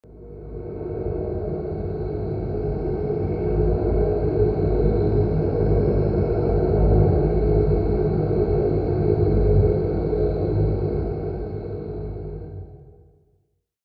temple_drone.mp3